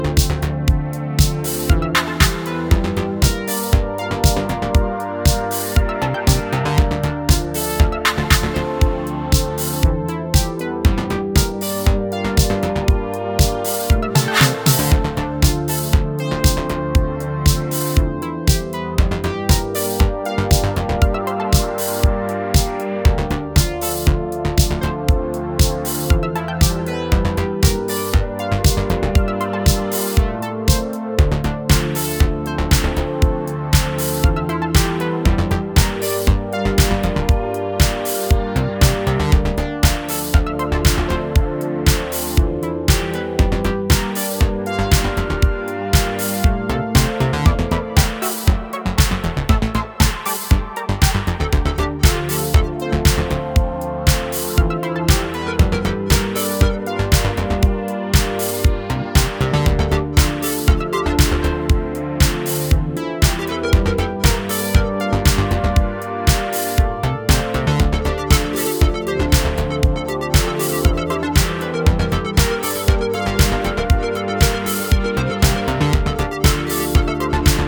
A timeless track without a doubt!